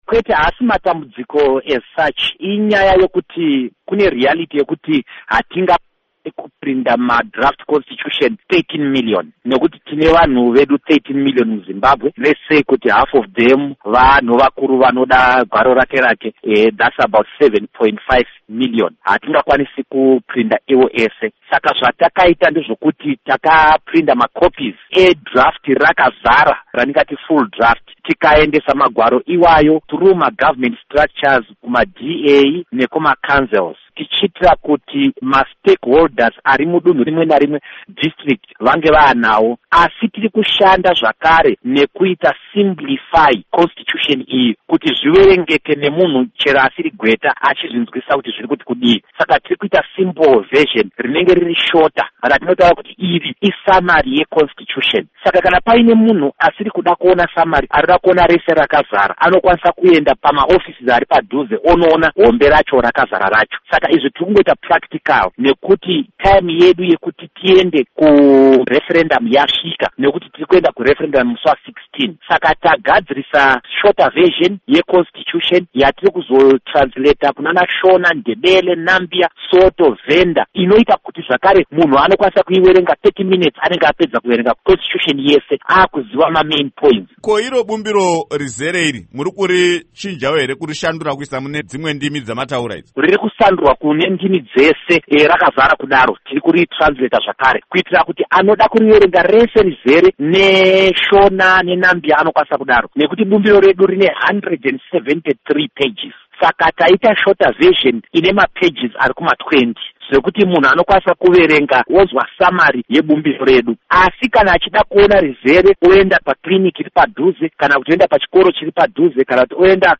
Embed share Hurukuro naVaDouglas Mwonzora by VOA Embed share The code has been copied to your clipboard.